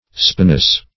Spinous \Spi"nous\, a.